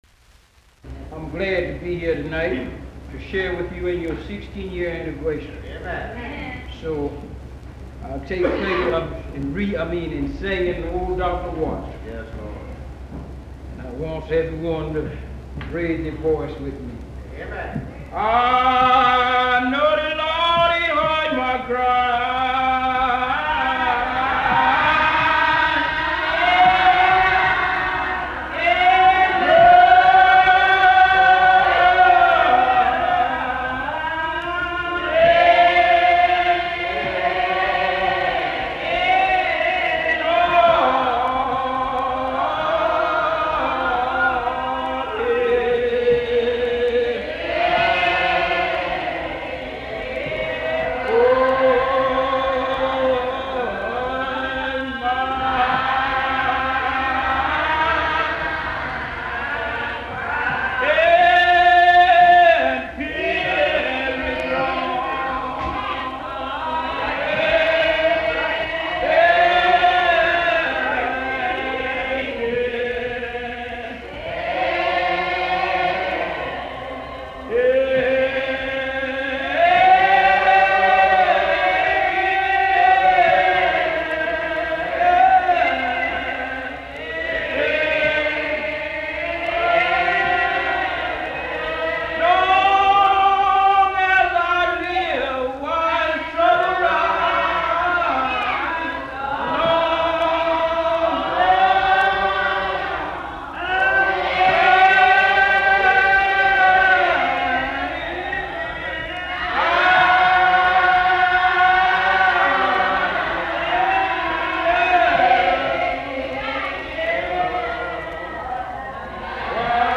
Music from the south : field recordings taken in Alabama, Lousiana and Mississippi.
piano: When the River Cease to Flow